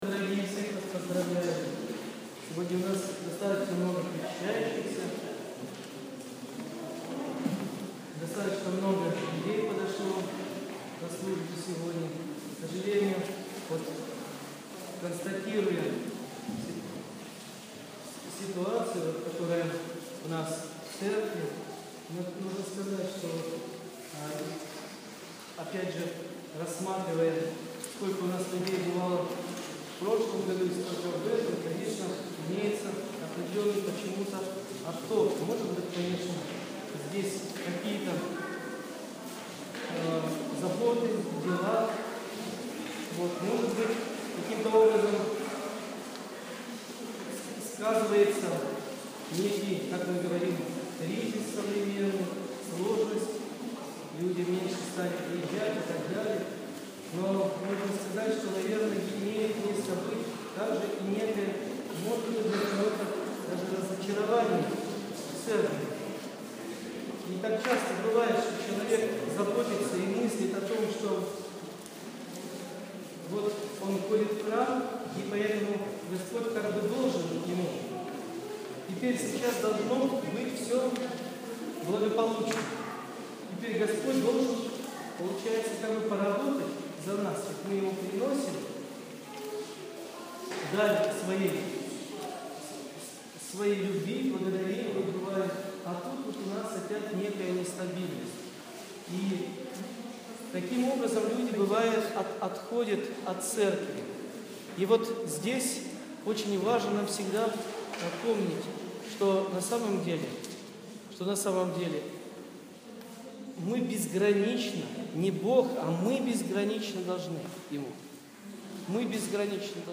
28.06.15. Проповедь настоятеля после Божественной Литургии.